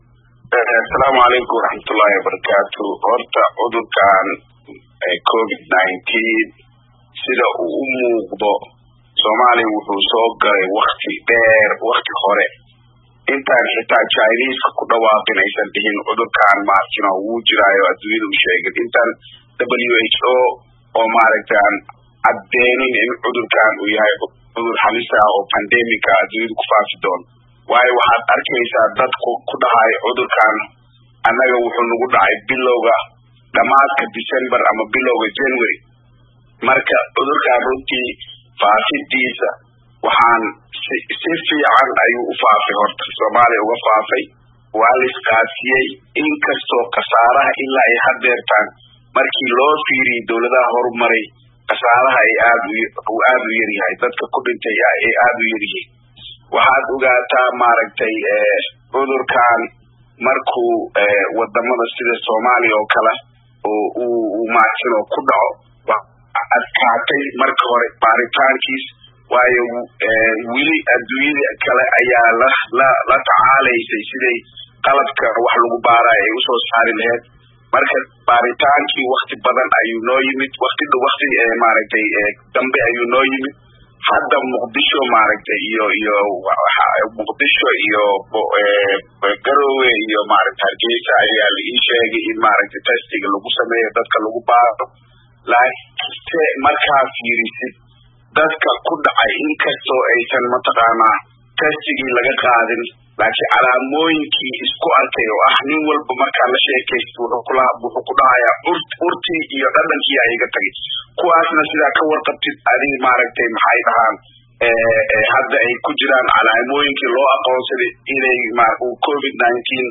Waraysi